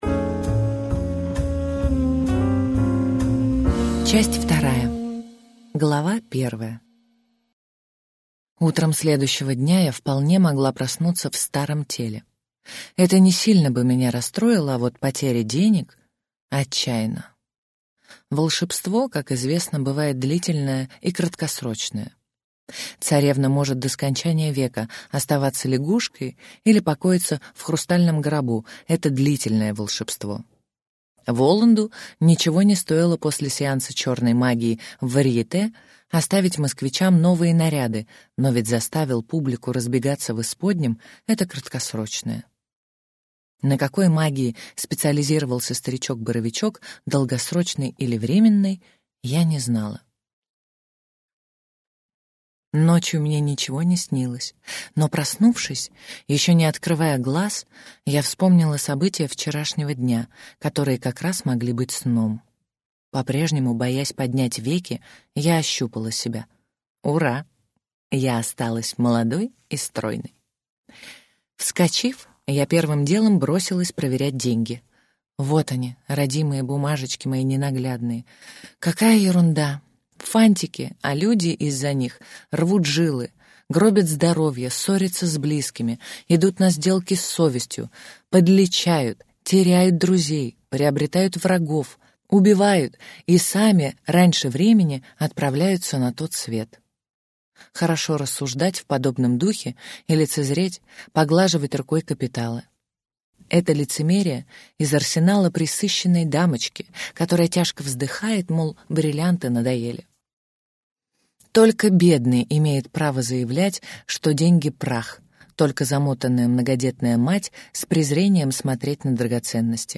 Аудиокнига Про девушку, которая была бабушкой - купить, скачать и слушать онлайн | КнигоПоиск